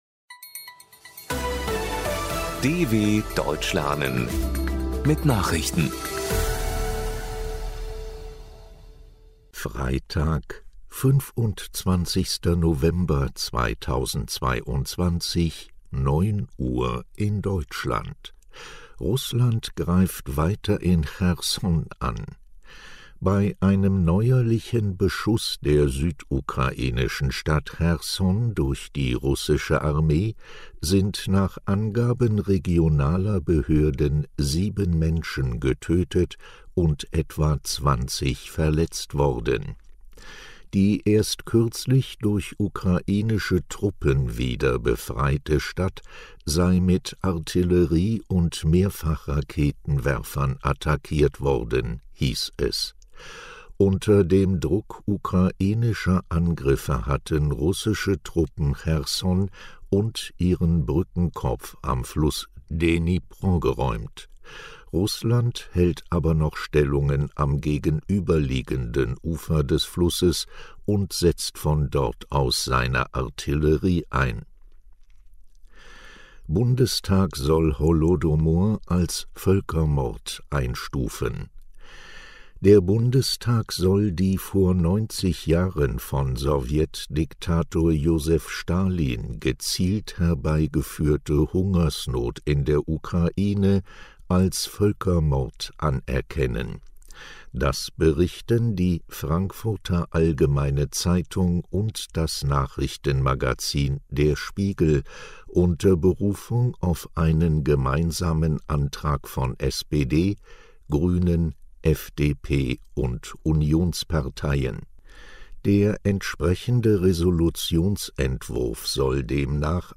25.11.2022 – Langsam gesprochene Nachrichten
Trainiere dein Hörverstehen mit den Nachrichten der Deutschen Welle von Freitag – als Text und als verständlich gesprochene Audio-Datei.